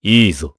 Clause_ice-Vox_Happy4_jp.wav